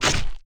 Boss3ZombieAttack1.mp3